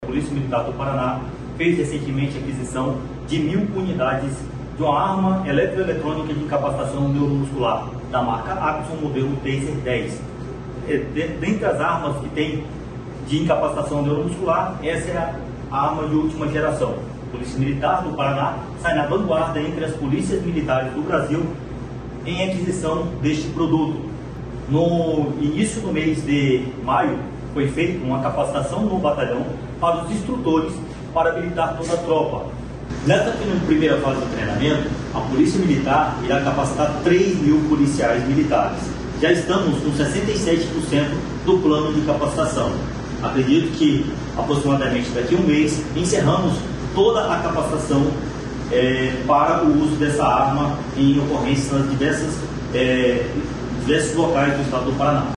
Sonora do capitão da Polícia Militar